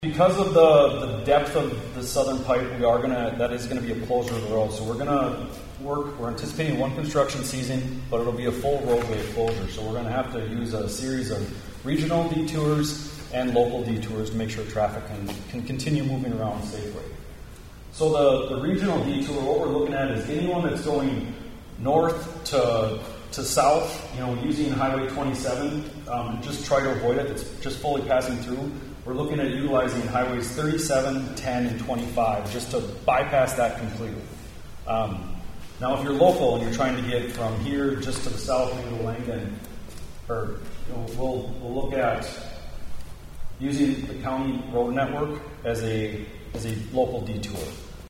BRITTON, S.D.(HubCityRadio)- On Thursday, the SD Department of Transportation hosted a public forum addressing the construction of SD HWY 27 that will be happening just south of Britton.